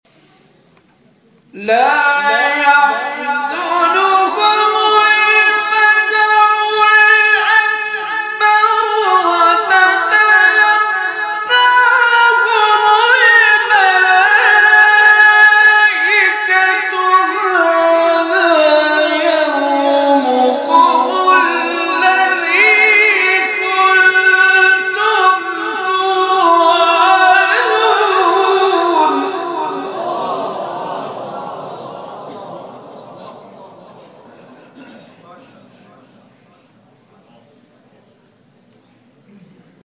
گروه شبکه‌های اجتماعی: فرازهای صوتی از تلاوت قاریان ممتاز و بین المللی کشور که در شبکه‌های اجتماعی منتشر شده است، می‌شنوید.